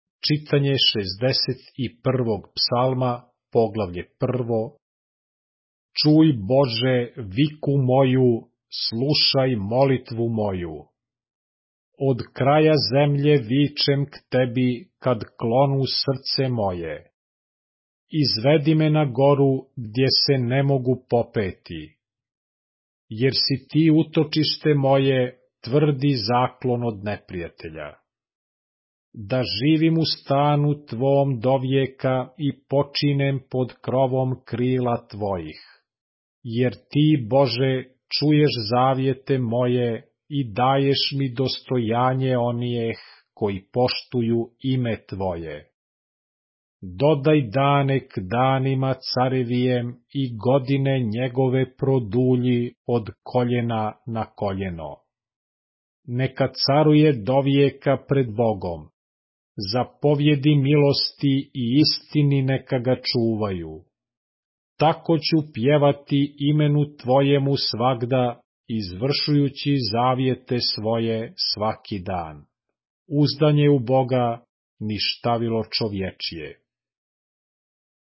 Psalmi / SVETO PISMO - čitanje - mp3